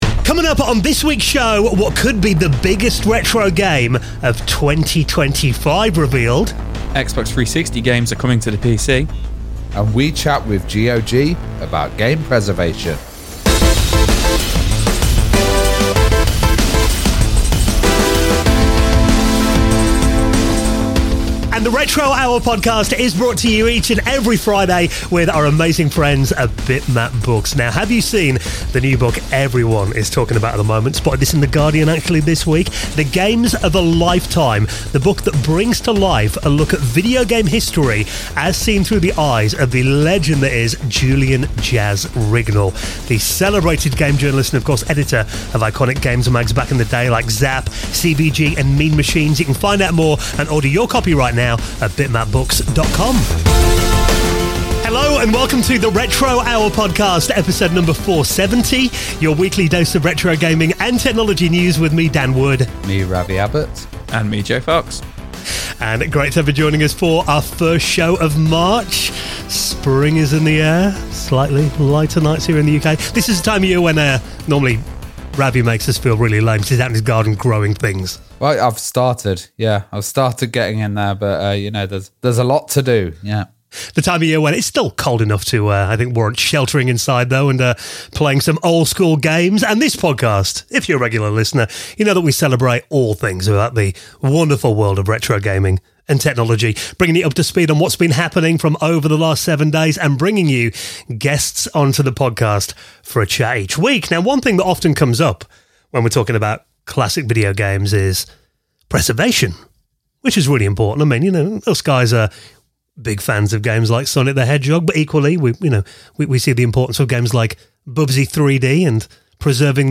44:05 - Good Old Game Interview